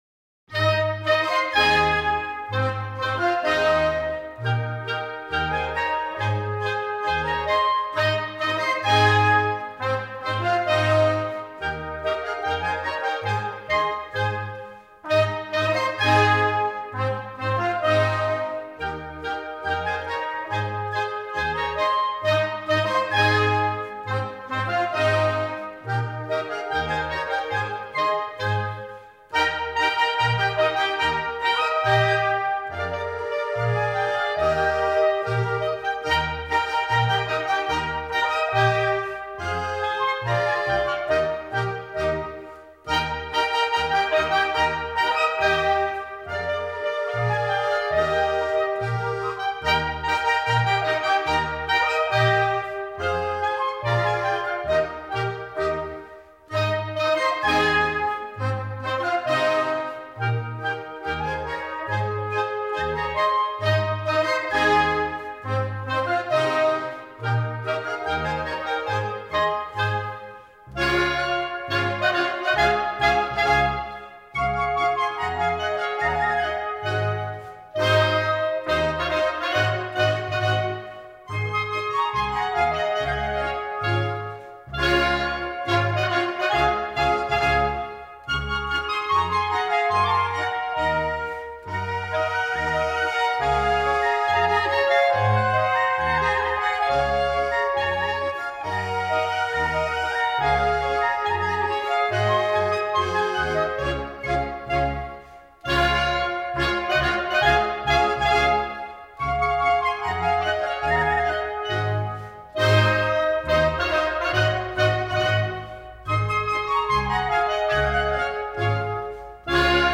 La Rebedoulée: Les cros en jambe – Tripped up (Polka)